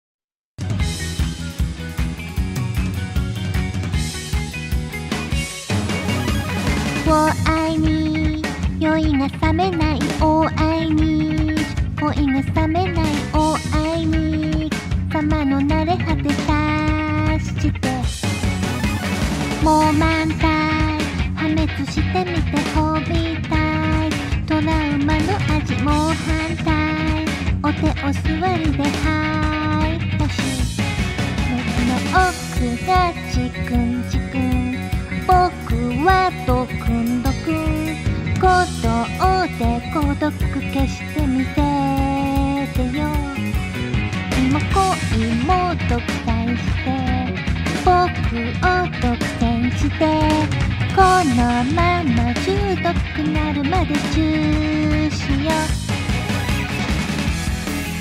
Singing
Karaoke Jpn Recording